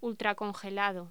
Locución: Ultracongelado
voz